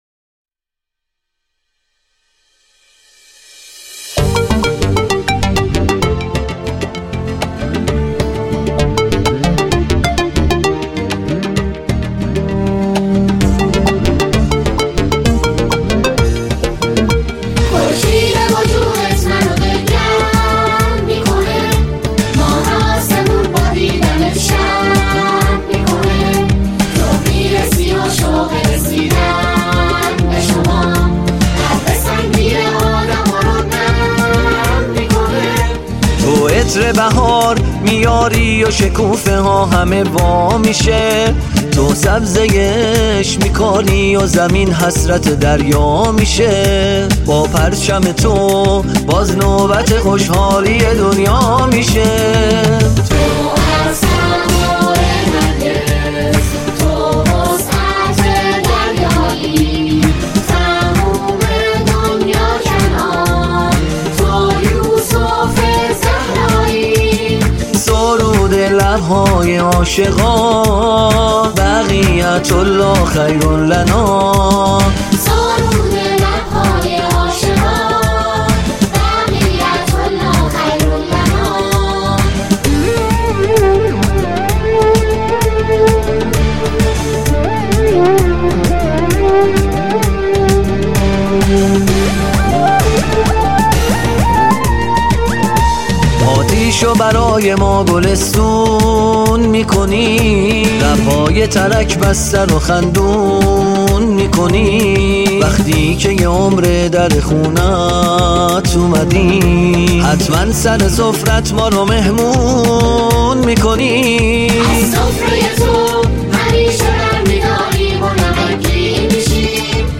ژانر: سرود